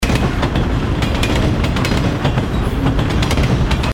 trainsound_0002.mp3